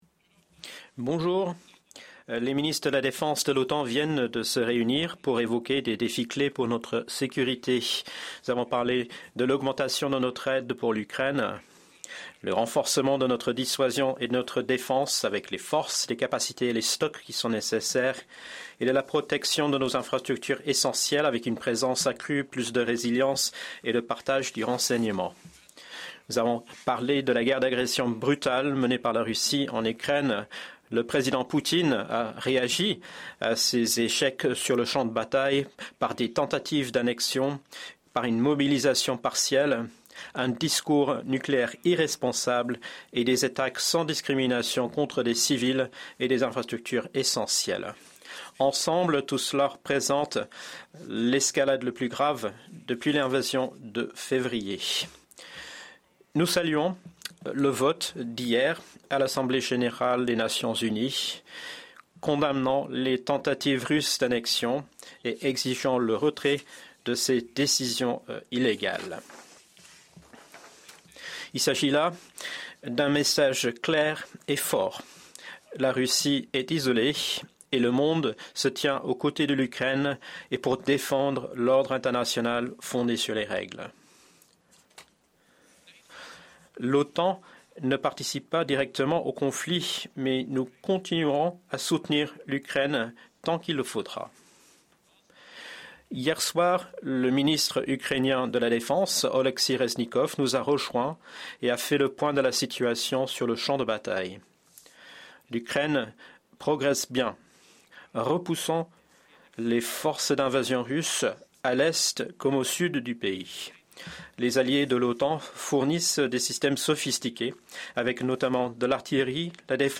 Press conference
by NATO Secretary General Jens Stoltenberg following the meetings of NATO Defence Ministers